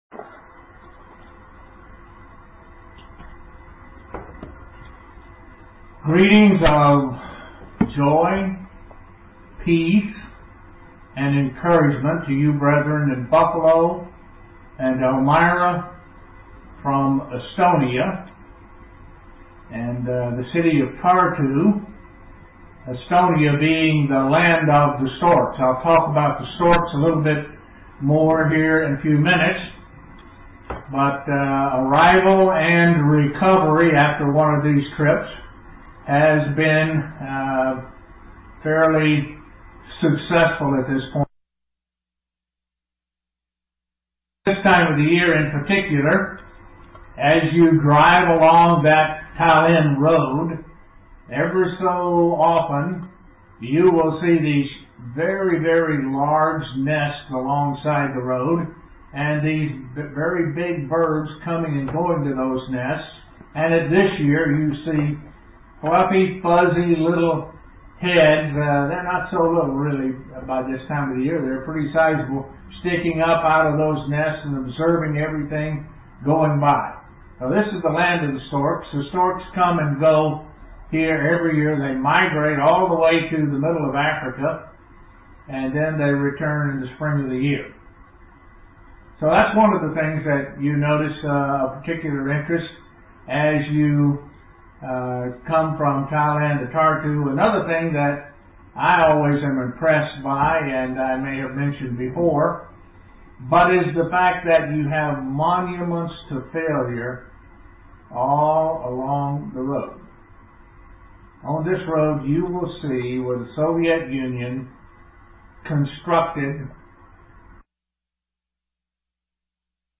God gives physical symbols to understand spiritual things–water being one to represent the Holy Spirit. (Broadcast from Estonia)
(Broadcast from Estonia) UCG Sermon Studying the bible?